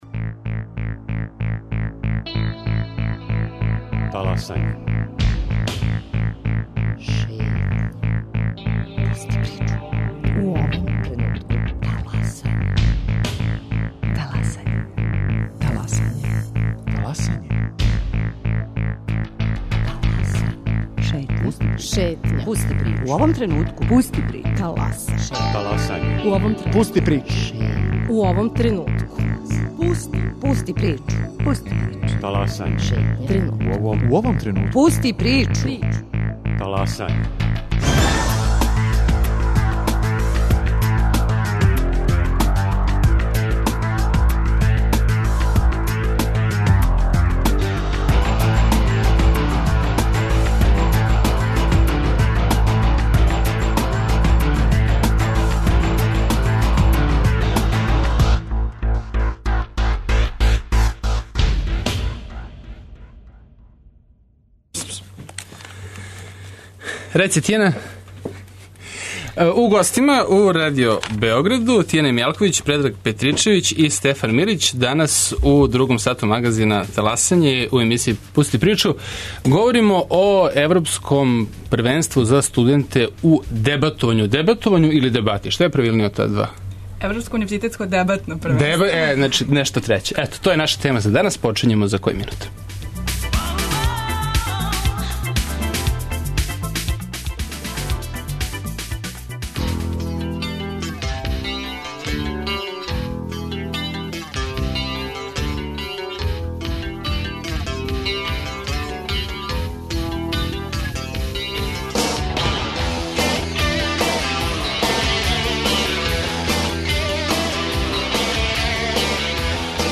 Шта нас учи дебата и недостаје ли праве дебате у јавности, као и које су актуелне теме око којих се ломе копља на турниру у Београду, разговараћемо с гостима - такмичарима и организаторима.